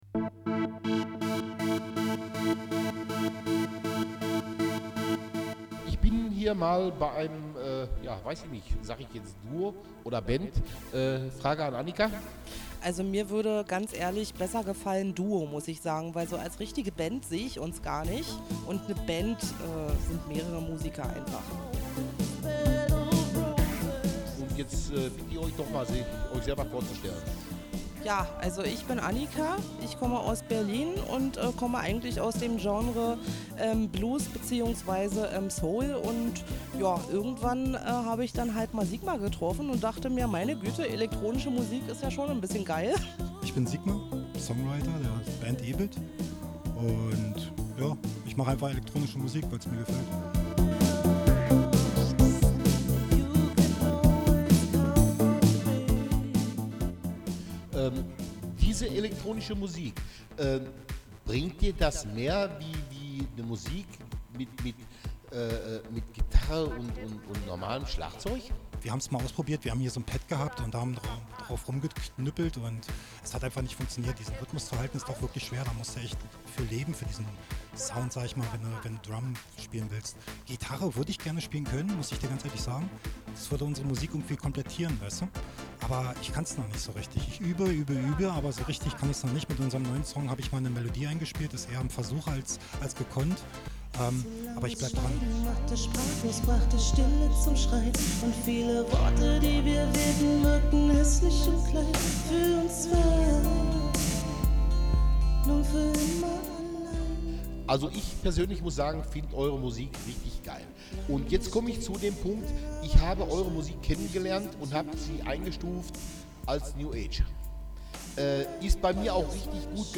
chill out , dance music, club music and synthpop